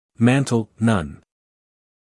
英音/ ˈmænt(ə)l / 美音/ ˈmæntl /